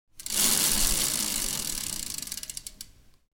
دانلود آهنگ دوچرخه 14 از افکت صوتی حمل و نقل
جلوه های صوتی
دانلود صدای دوچرخه 14 از ساعد نیوز با لینک مستقیم و کیفیت بالا